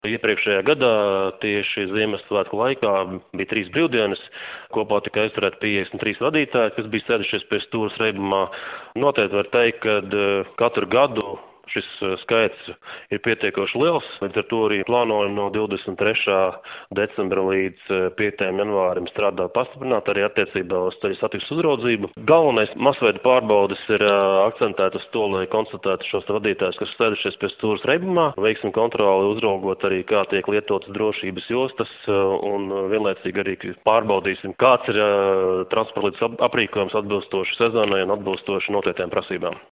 Policija centīšoties būt redzama visā valsts teritorijā, to intervijā Skonto mediju grupai apliecināja Valsts policijas (VP) Reaģešanas pārvaldes priekšnieks Juris Jančevskis.